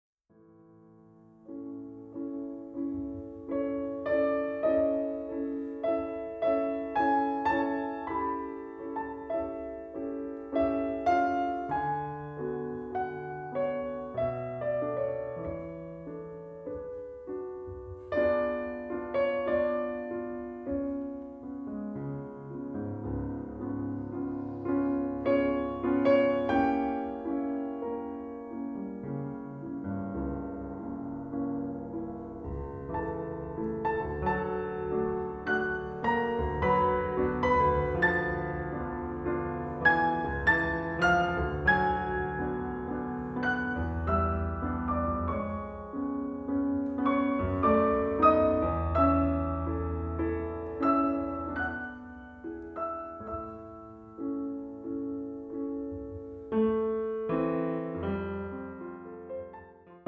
Genre : Classique